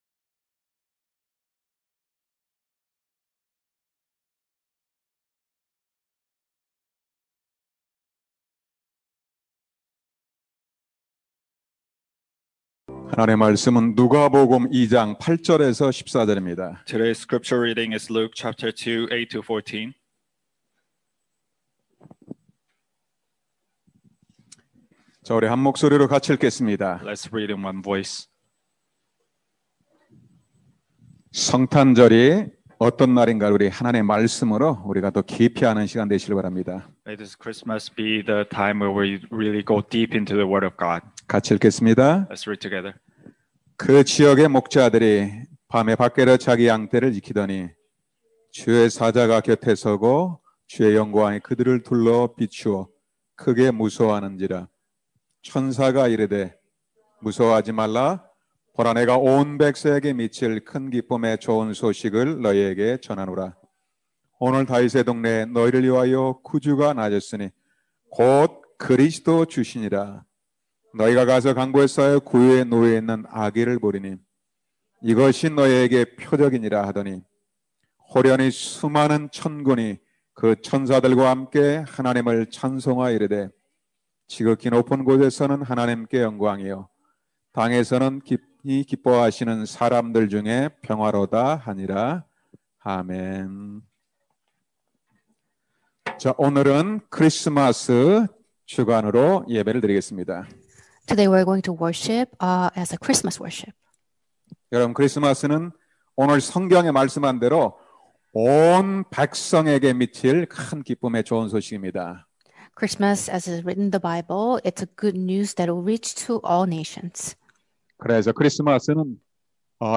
Sermon –Why is Christmas a Good News?